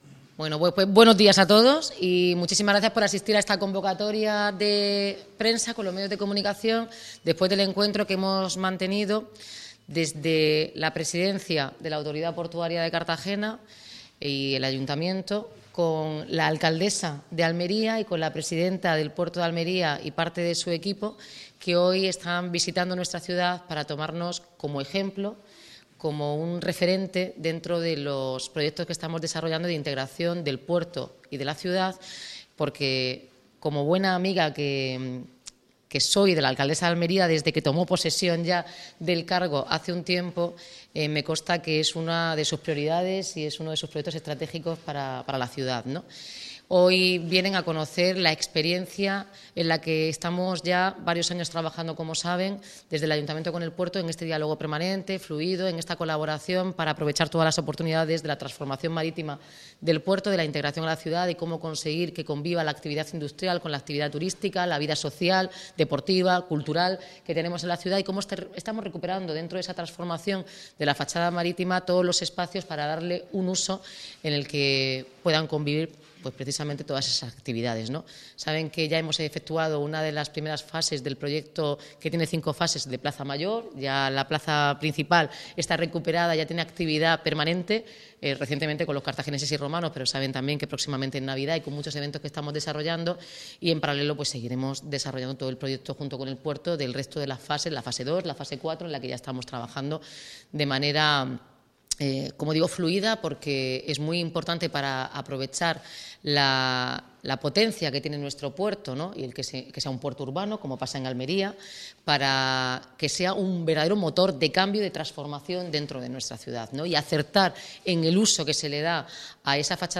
Enlace a Declaraciones de Noelia Arroyo, la alcaldesa de Almería María del Mar Vázquez y el vicepresidente del Puerto de Cartagena, Pedro Pablo Hernández.